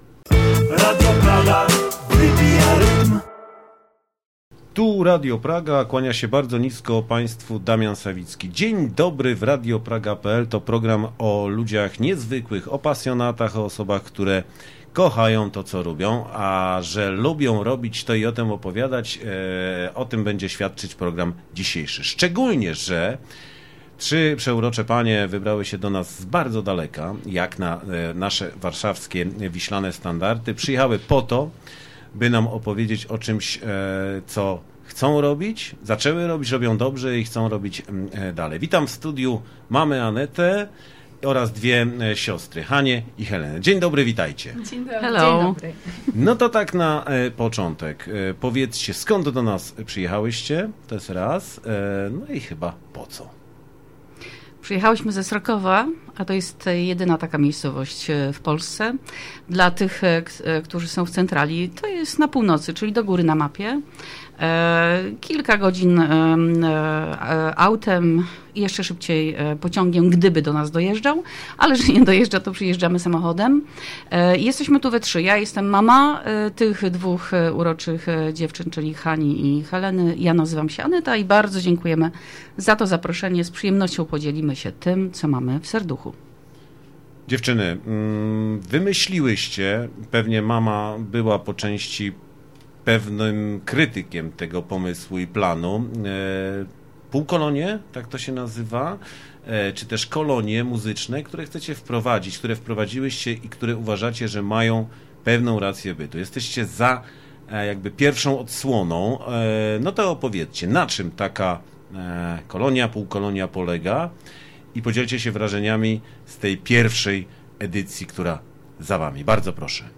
Nie zastanawiając się długo, zaprosiliśmy dziewczyny do nas na wywiad.